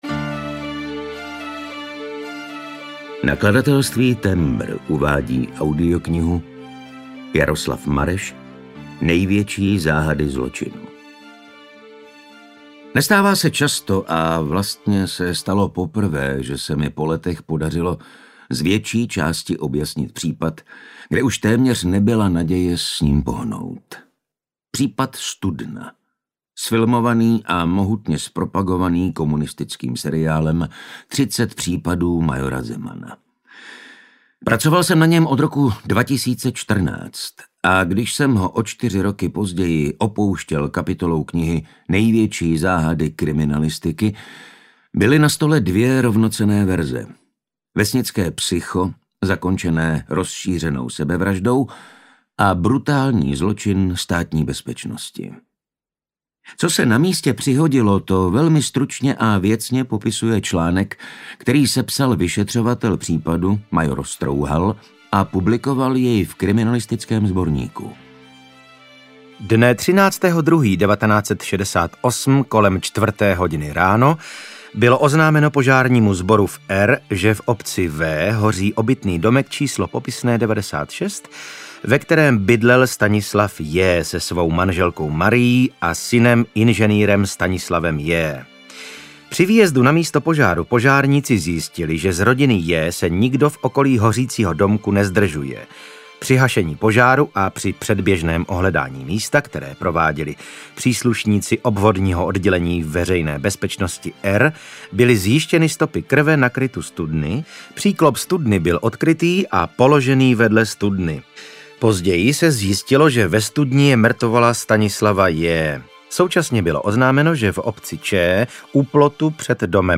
Největší záhady zločinu audiokniha
Ukázka z knihy